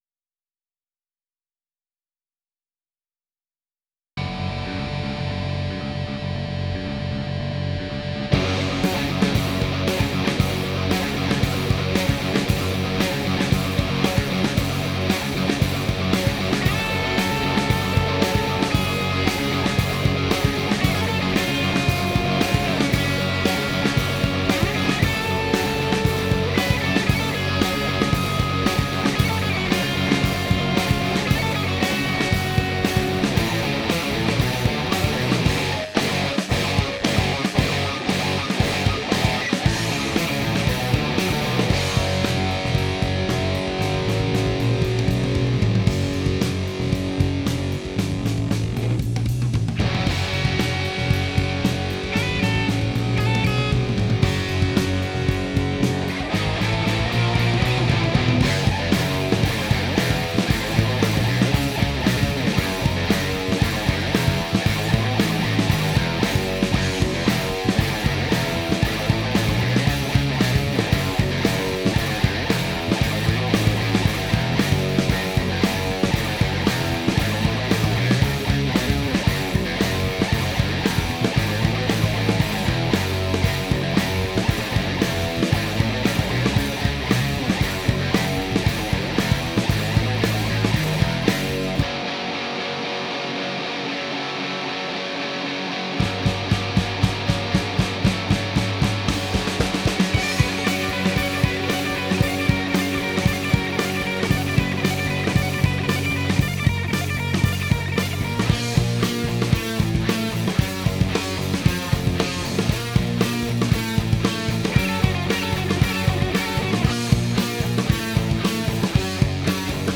Instrumental Guitar Rock (2018)
It really brings out the players on their instrumentation.